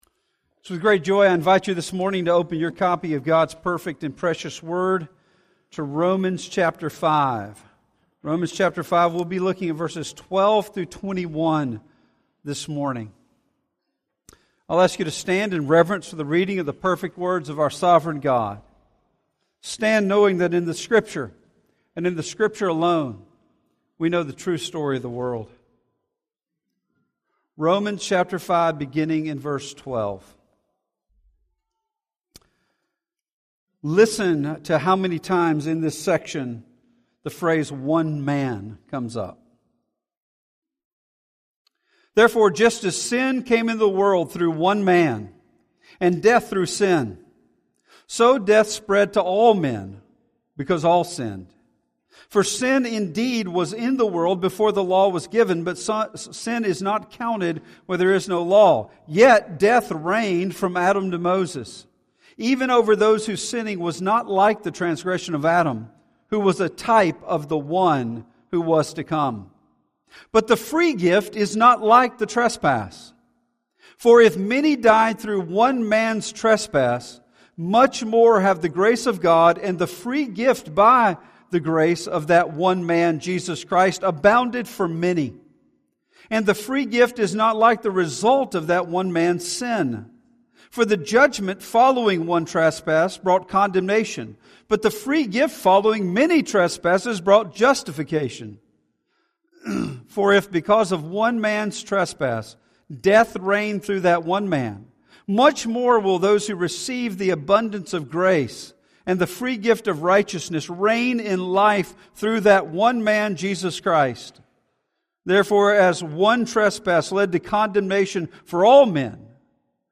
In this week's sermon in our "The Gospel" series we see how every person is either represented by Adam or Christ.